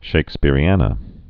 (shāk-spîrē-ănə, -änə)